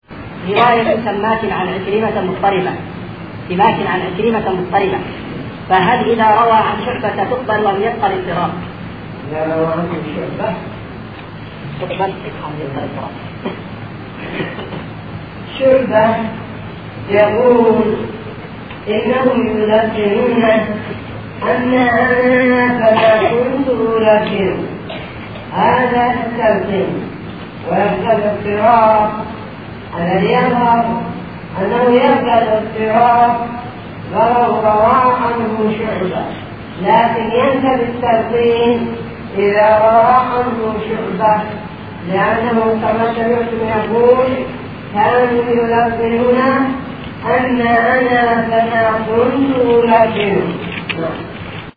فتاوى